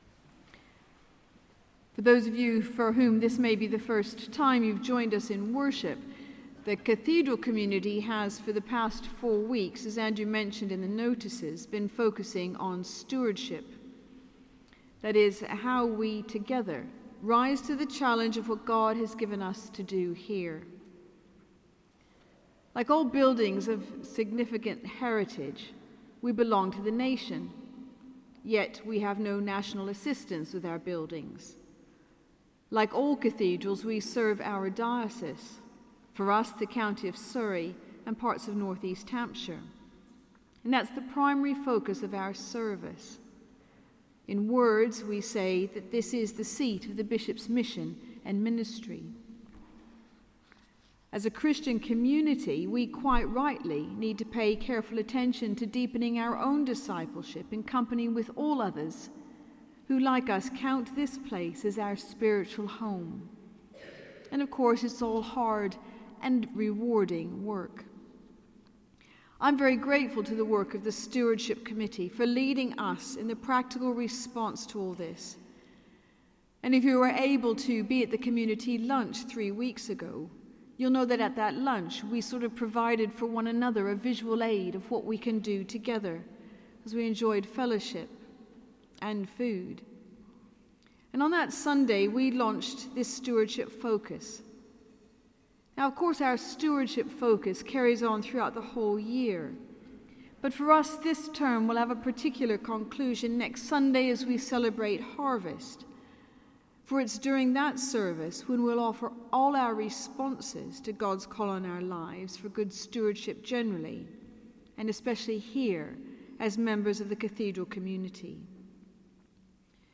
Sermon: Sung Eucharist 4th October 2015